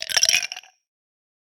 SMS Alert
Notification sound from the 2018 LG Stylo 4 Plus.